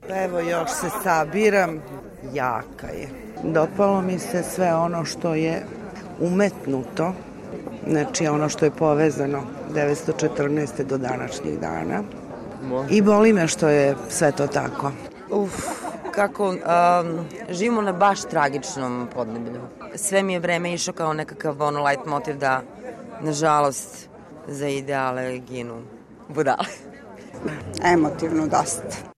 Slušate reakcije gledalaca nakon prve reprize u Bitef teatru:
Publika o predstavi "Mali mi je ovaj grob"